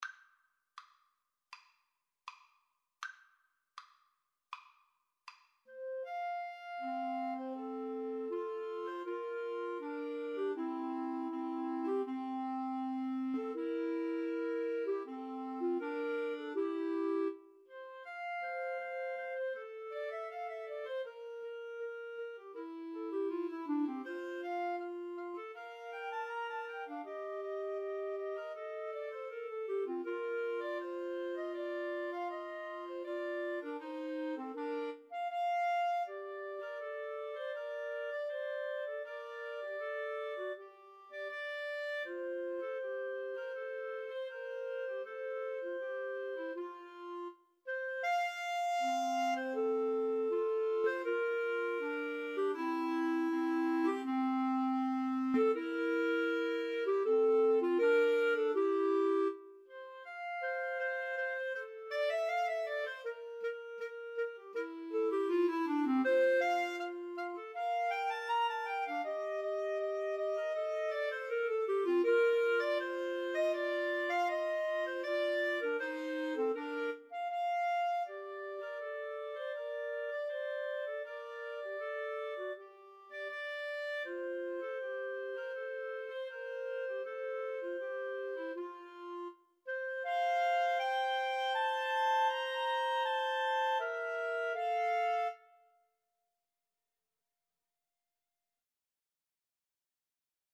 = 80 Andante
Classical (View more Classical Clarinet Trio Music)